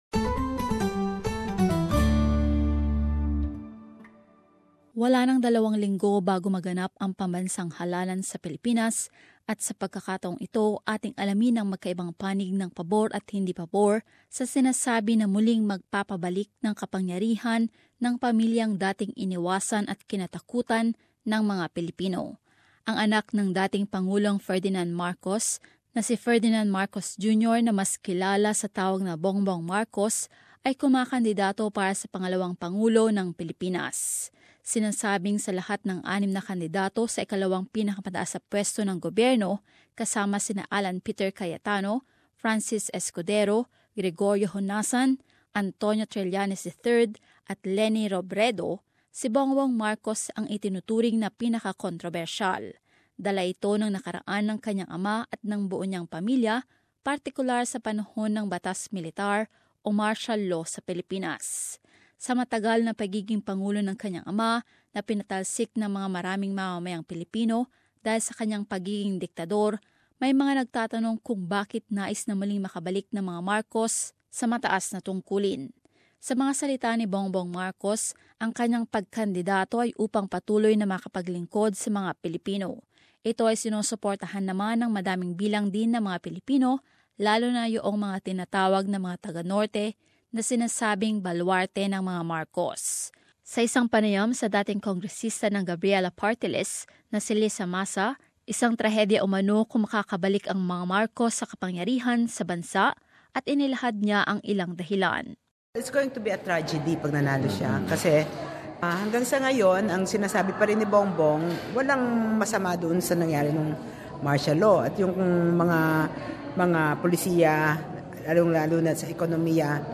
In this report, we get both sides who favor or not of the return of a Marcos in one of the highest office in the Philippines.